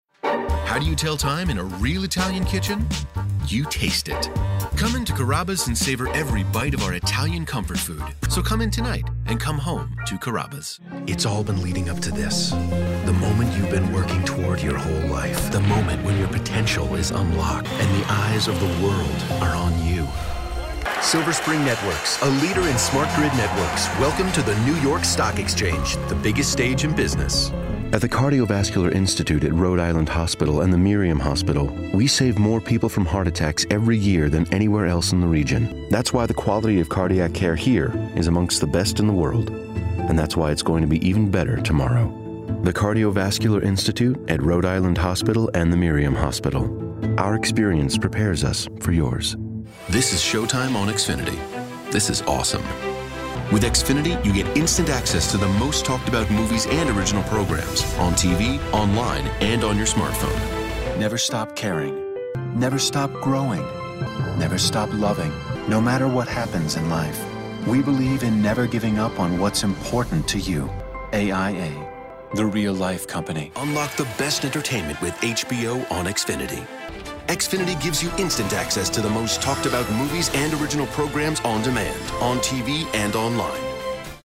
Voice Over reel 3 (friendly, conversational, trustworthy)